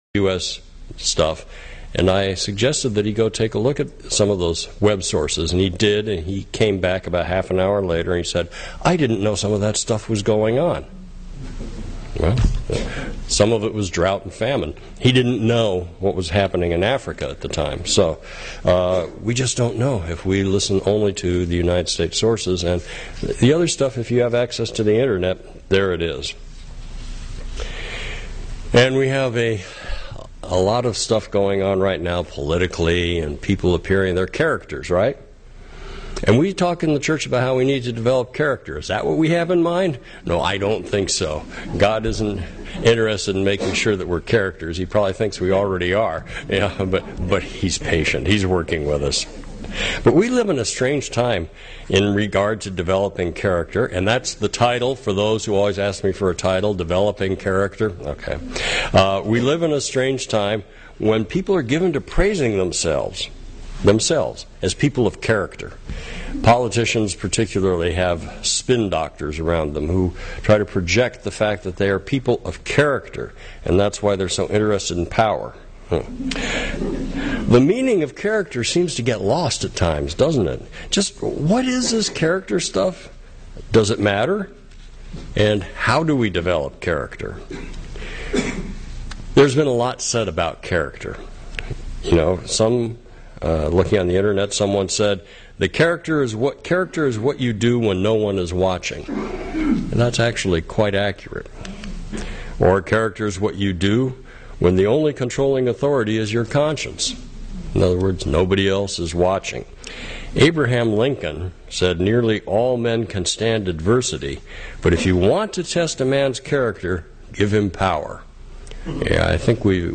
UCG Sermon Notes A partial list of Scriptures used: Act 6:8 And Stephen, full of faith and power, did great wonders and miracles among the people.